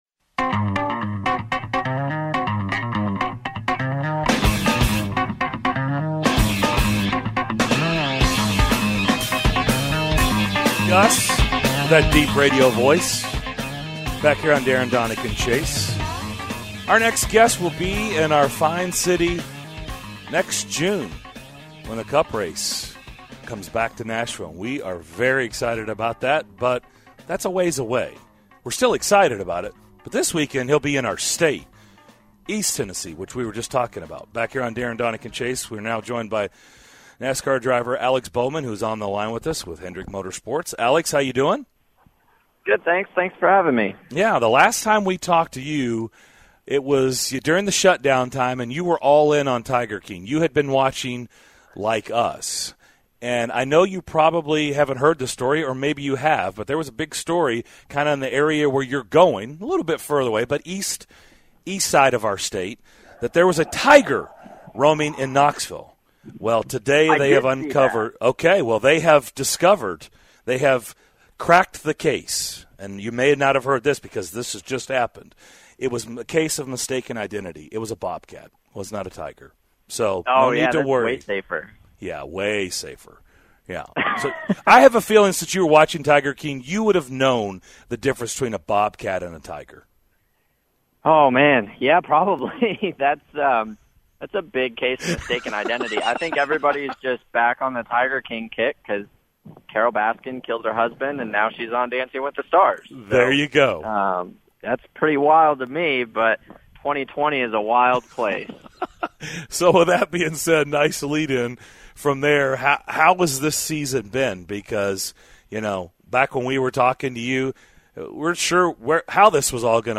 NASCAR driver of the 88 car, Alex Bowman, joined DDC to discuss this weekend's race in Bristol, the Knoxville "Tiger" and more!